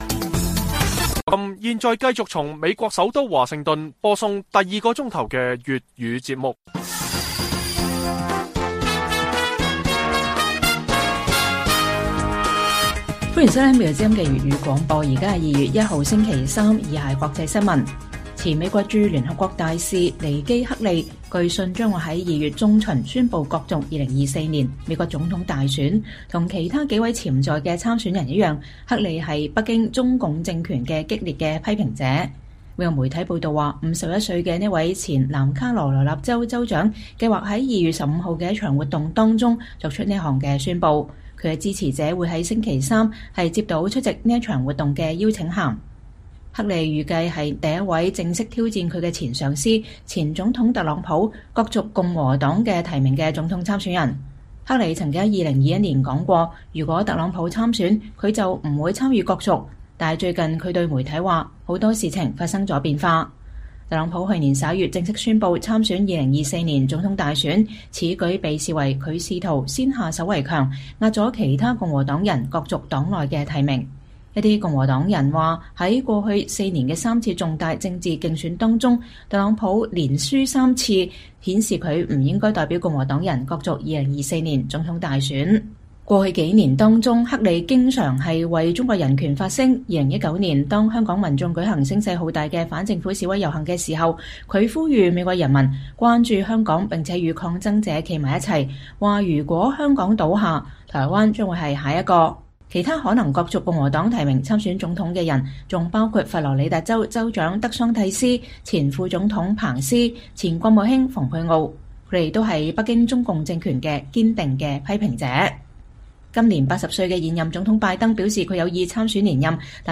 粵語新聞 晚上10-11點 : 台灣人對美半信半疑？觀察人士：中共操弄“疑美論”